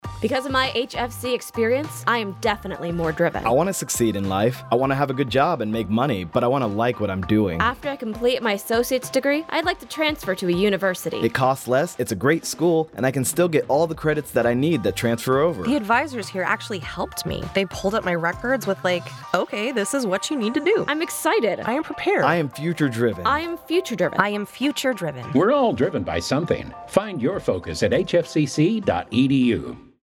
Radio/Audio Spots FY20-21